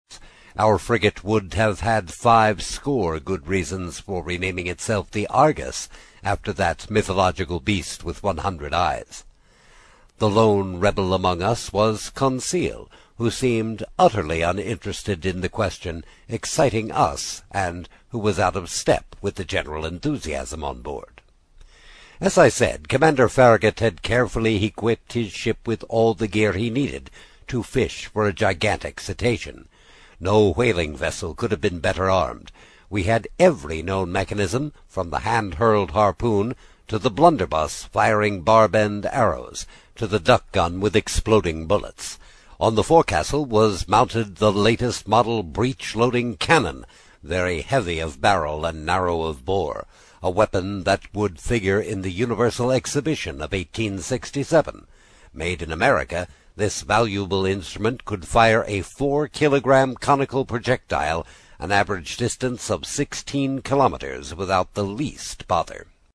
在线英语听力室英语听书《海底两万里》第34期 第4章 尼德兰(3)的听力文件下载,《海底两万里》中英双语有声读物附MP3下载